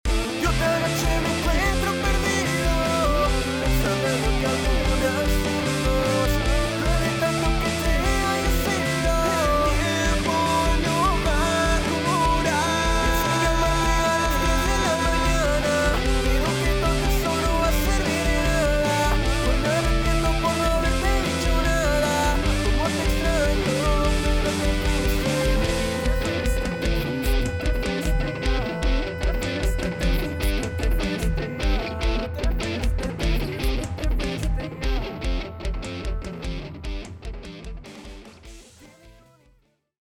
Género: Rock / Pop.